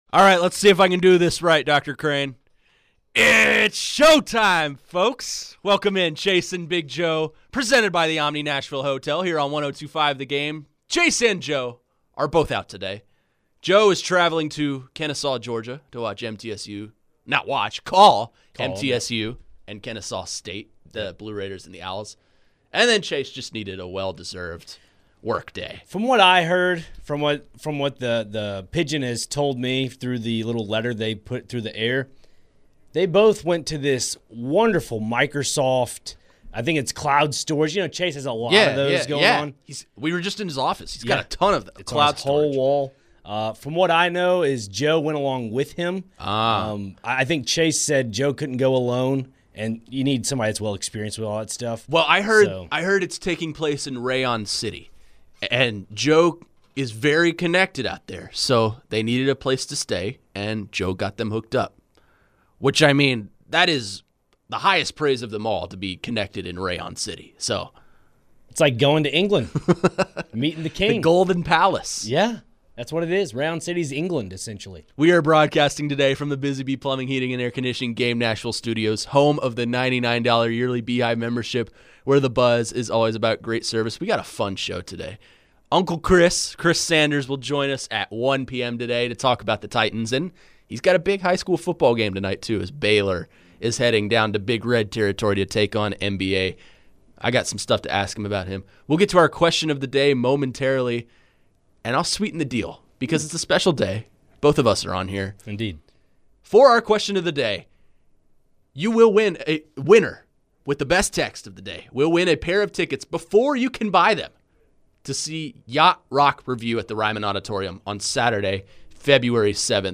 Later in the hour, the guys posed their question of the day to the audience and answered some phones.